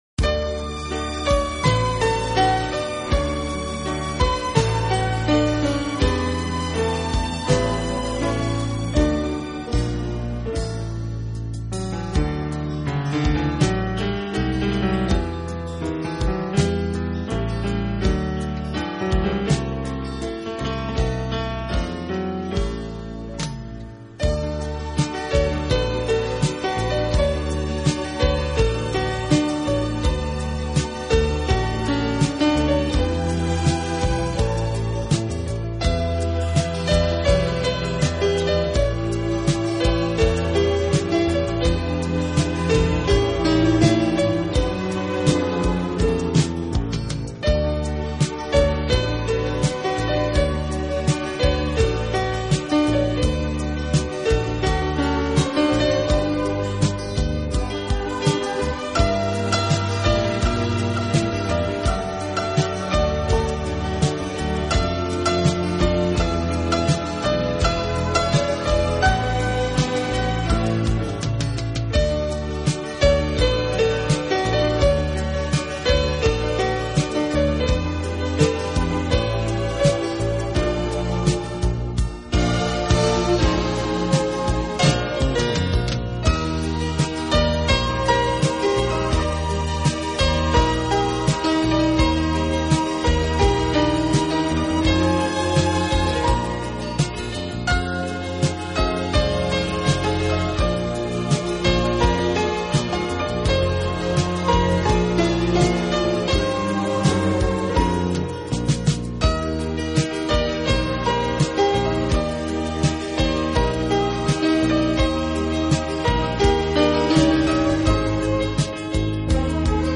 Genero/Genre: Instrumental